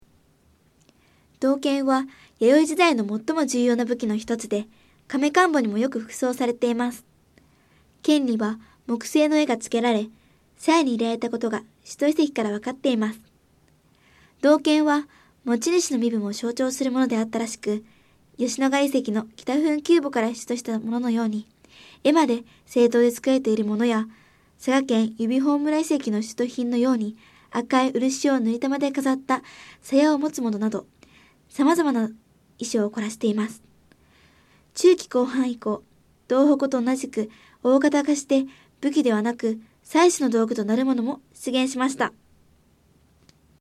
音声ガイド 前のページ ケータイガイドトップへ (C)YOSHINOGARI HISTORICAL PARK